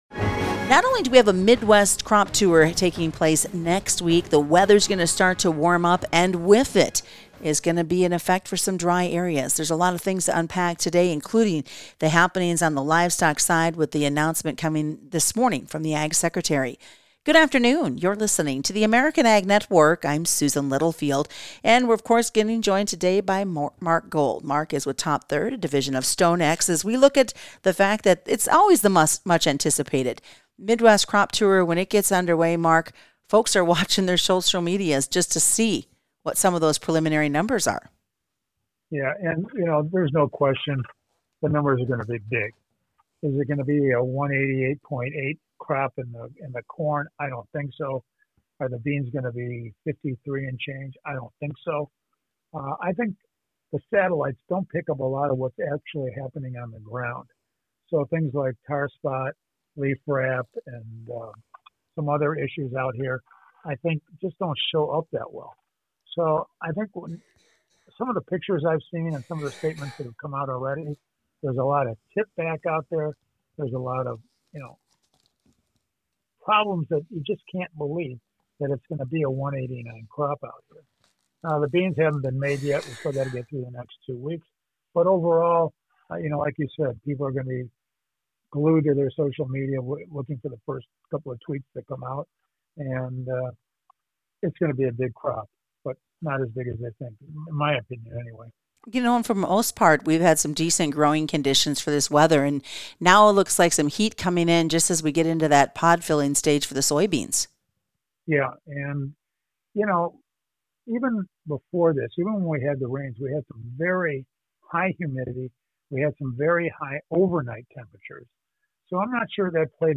Friday Market Wrap Up